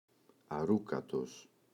αρούκατος [a’rukatos]